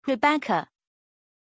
Pronunția numelui Rebecka
pronunciation-rebecka-zh.mp3